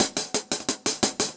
Index of /musicradar/ultimate-hihat-samples/175bpm
UHH_AcoustiHatC_175-04.wav